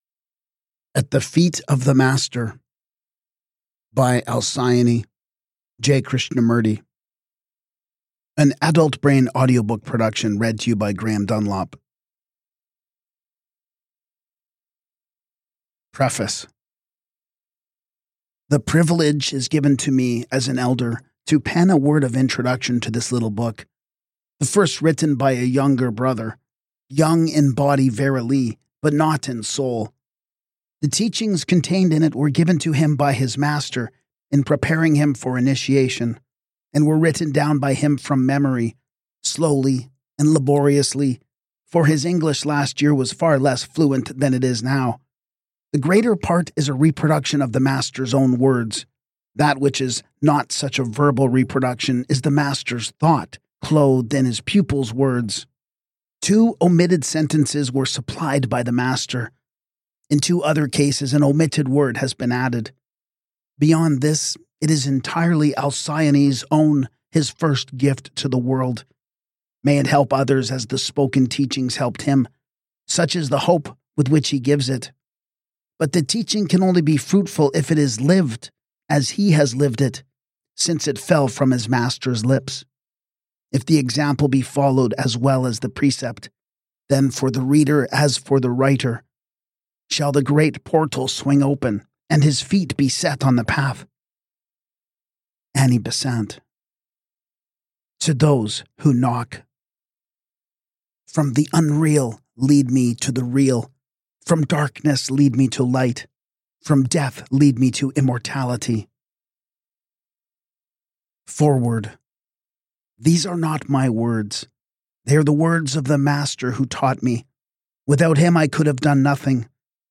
In audiobook form, these teachings become especially powerful: calm, reflective, and perfectly suited for meditation, contemplation, or quiet moments of personal growth.